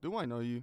Voice Lines / Dismissive
do i know you.wav